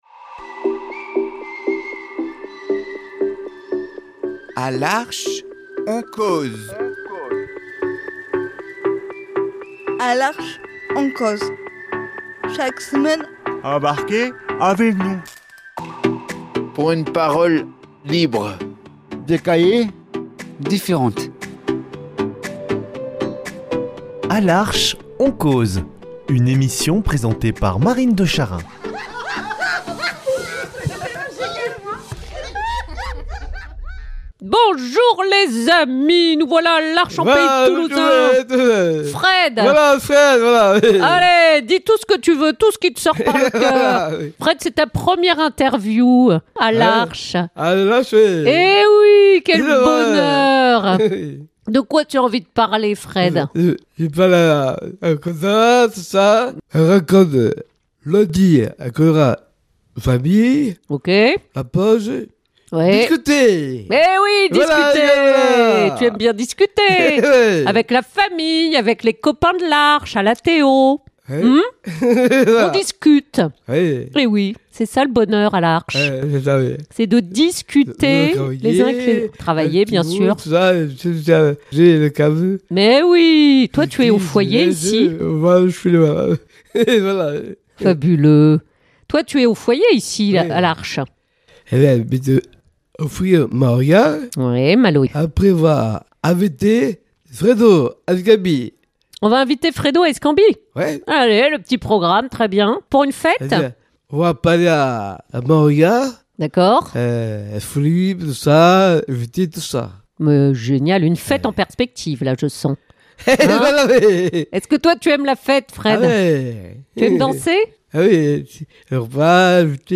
Décidant que le moment était venu, il s’est laissé embarquer par la magie du studio, du micro, du cœur à cœur libre et vrai.